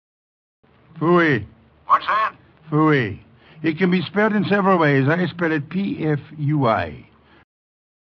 pfui audio  *Sidney Greenstreet's pronunciation (from the radio show, "The Party for Death")
Pfui_Greenstreet.mp3